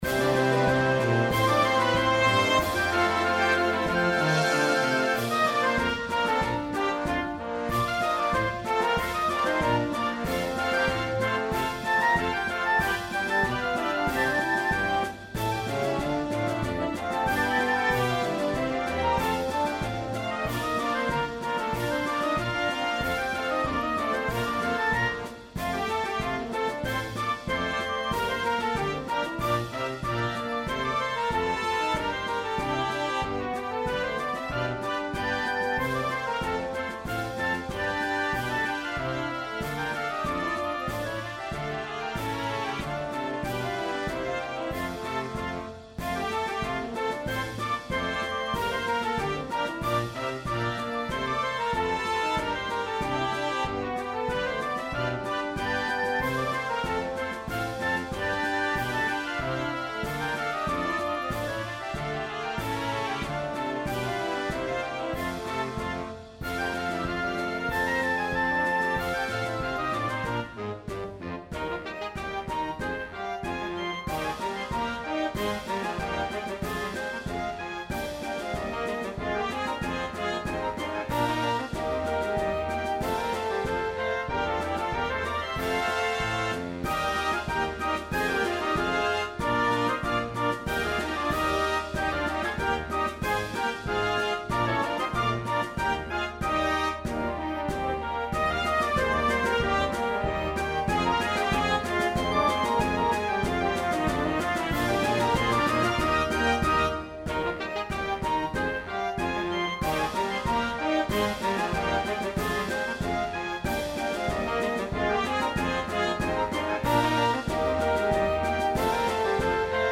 Marcia